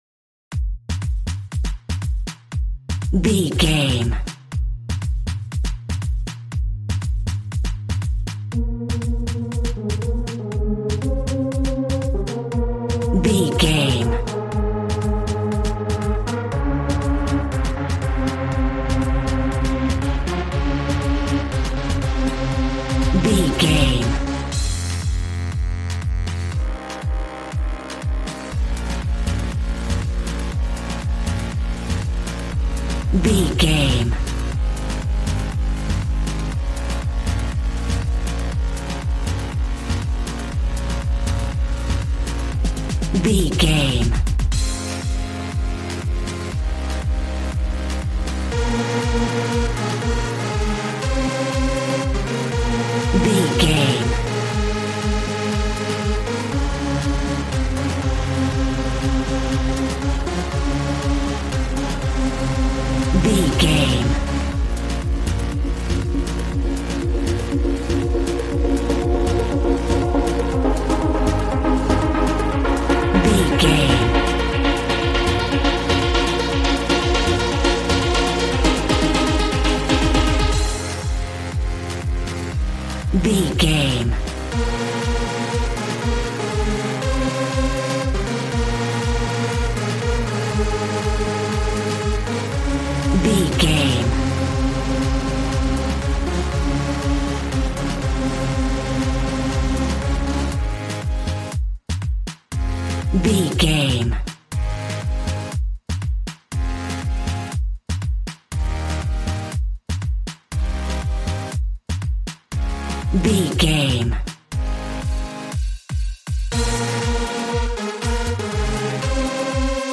Aeolian/Minor
Fast
uplifting
groovy
synthesiser
drums